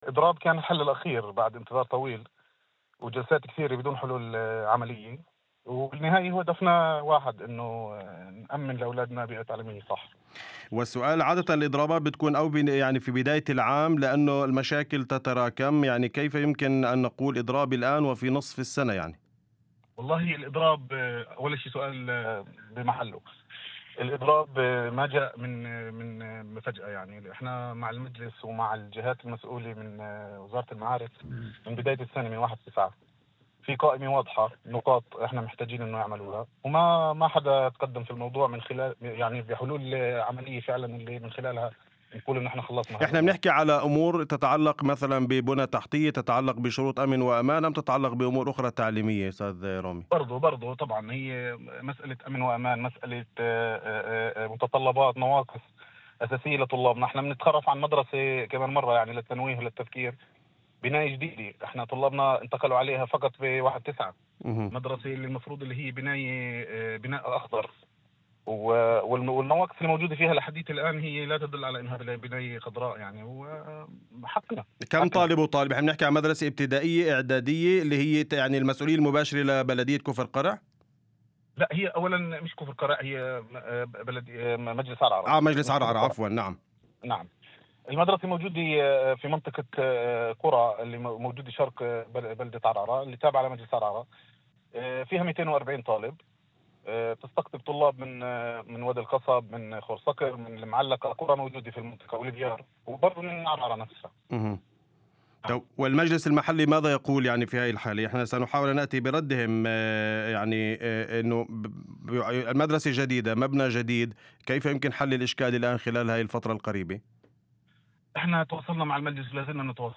في مداخلة لإذاعة الشمس ضمن برنامج "أول خبر"